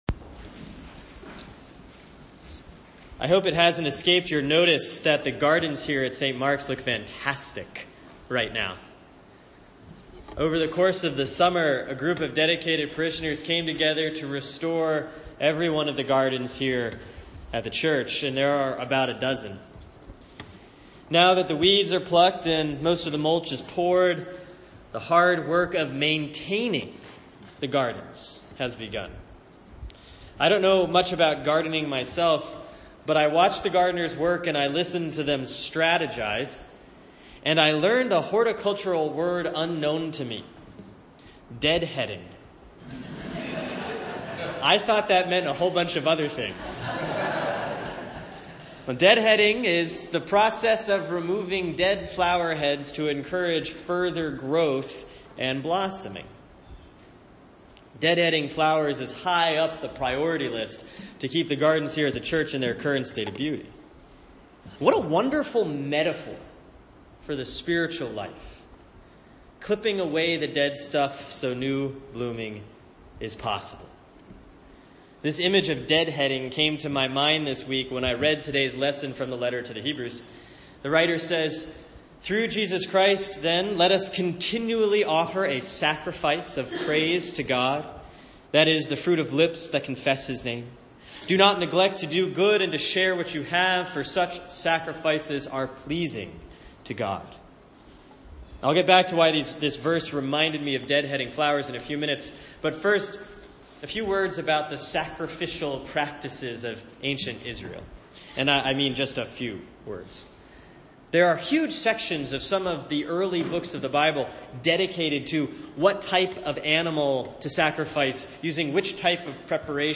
Sermon for Sunday, August 28, 2016 || Proper 17C || Hebrews 13:1-8, 15-16